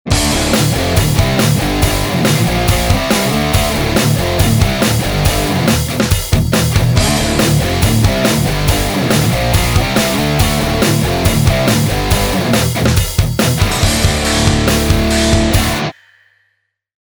Tak som dnes vecer opat dostal testovaciu naladu a vrhol som sa na dalsi test: Celestion Vintage30 - made in China vs. England
Ale podstatne je, ze to hra uplne inak!
Pre kutilov doplnim info o nahravani: na kazdej ukazke su nahrate 3 gitary, v 3 roznych poziciach mikrofonu (chcel som zachytit celu skalu zvuku repraku) a rozhodene v panorame (Left, center, Right)
Na boxoch som si oznacil stredy membran bielou paskou, aby mikrak bol v rovnakej pozicii a este aj vo vzdialenosti od membrany